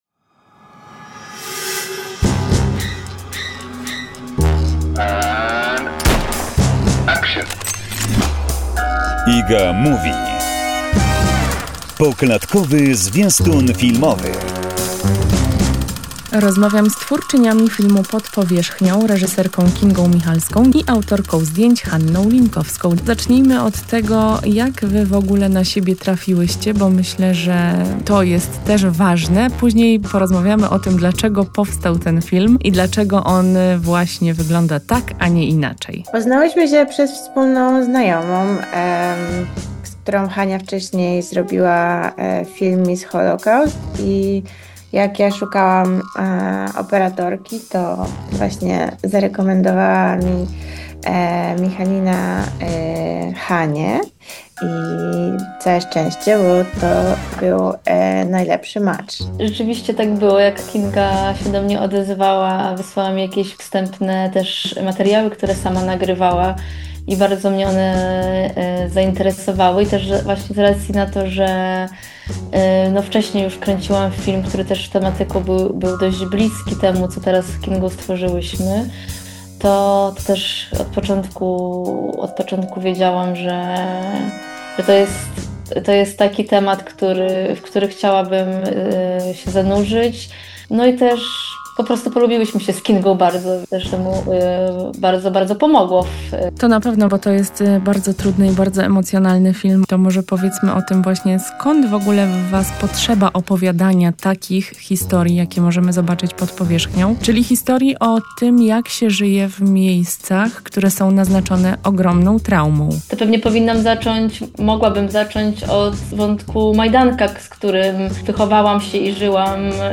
rozmowa o filmie „Pod powierzchnią”